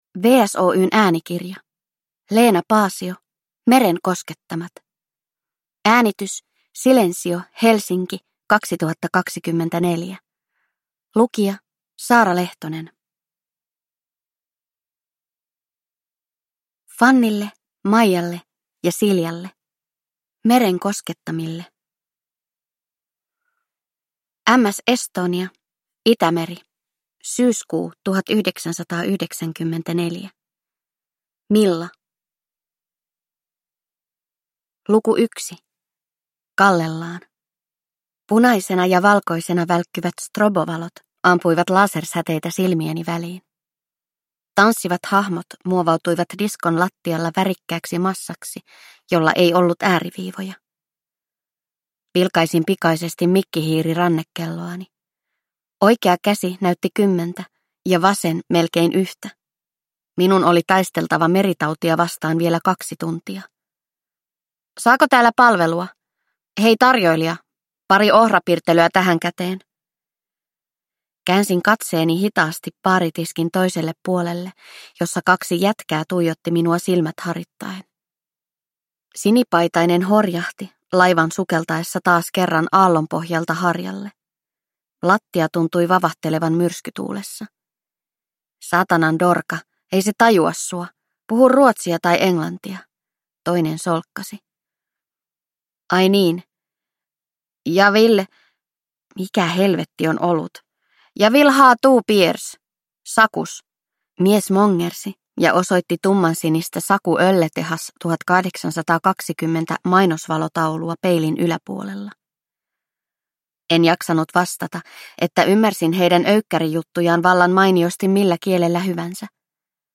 Meren koskettamat – Ljudbok